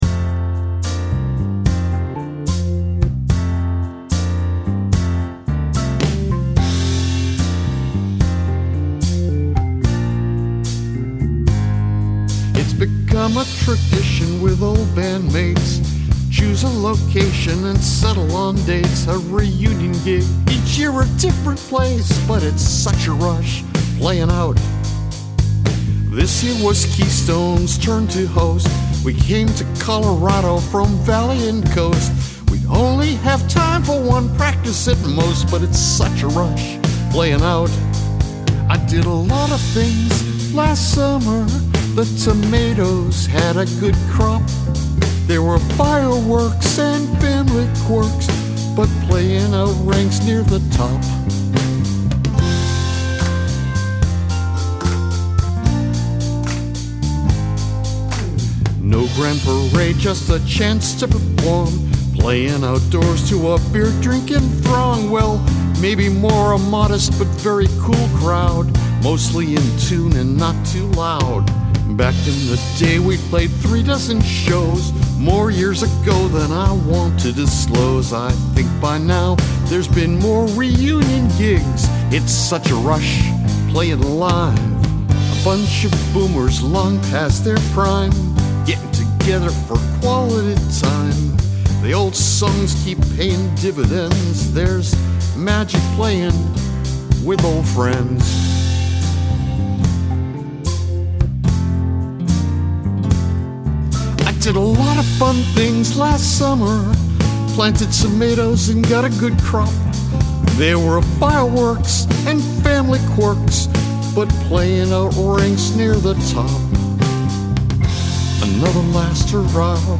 I like the chorus with the run down Bb to Amin etc.
Lovely instrumentation and a wonderful vocal.
I like the throwback feel of the music, it really fits the idea of getting the band back together! Nice organ too.